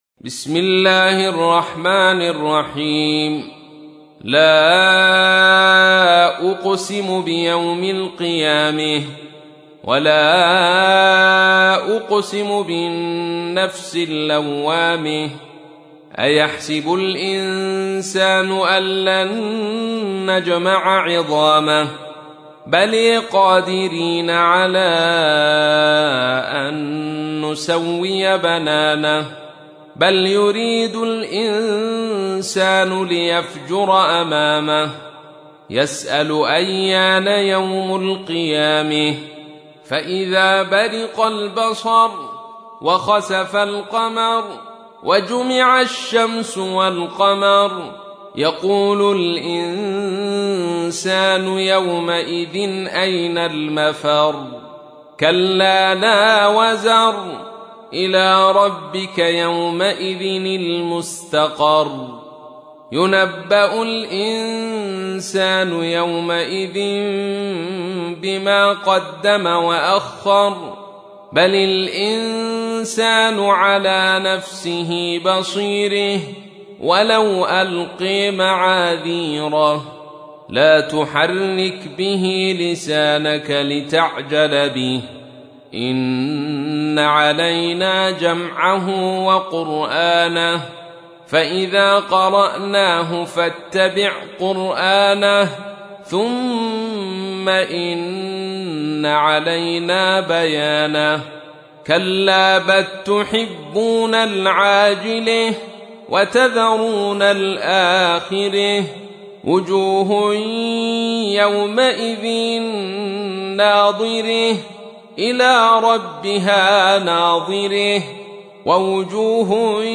تحميل : 75. سورة القيامة / القارئ عبد الرشيد صوفي / القرآن الكريم / موقع يا حسين